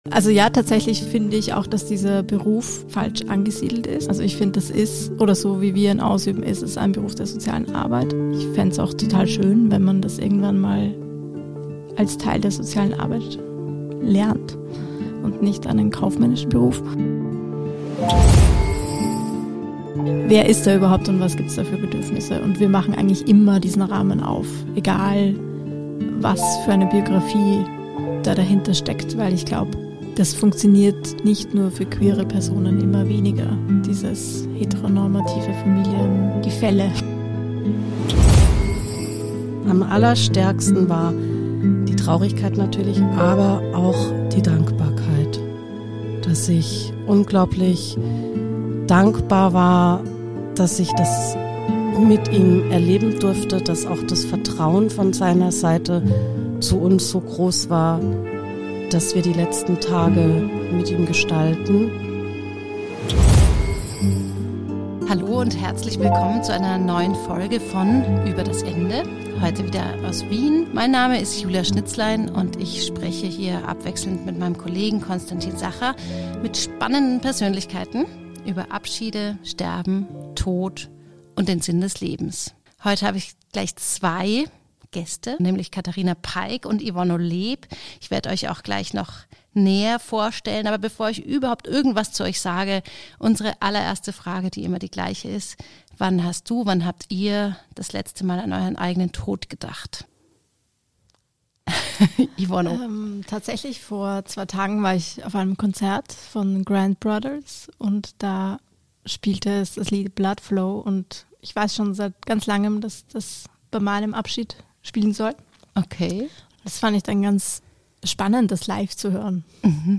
Im Gespräch erzählen sie, wie sie den assistierten Suizid erlebt haben und wie eine Begleitung in dieser Zeit aussehen kann.